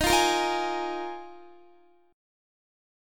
Listen to Ebsus2sus4 strummed